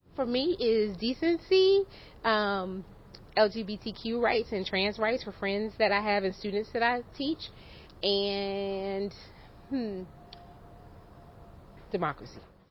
Hear from a voter